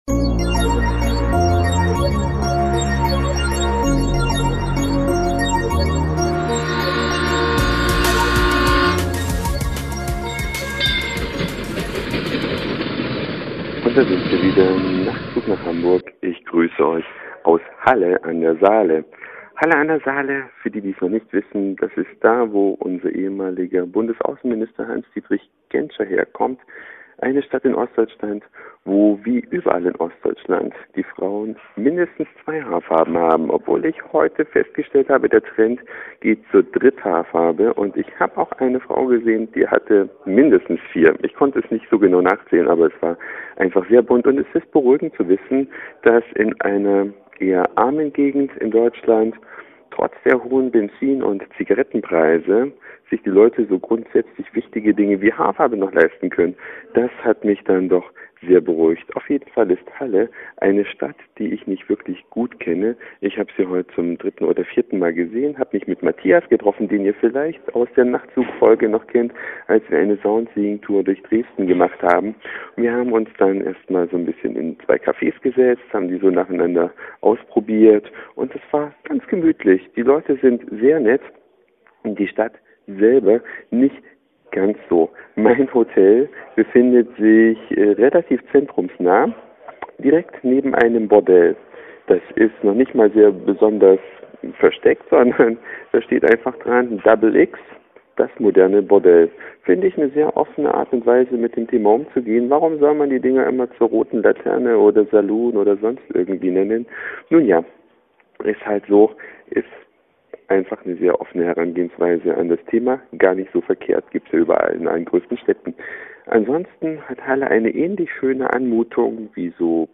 Telefonischer Podcast aus Halle an der Saale Mein Hotel befindet